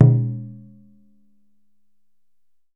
DJUN DJUN01L.wav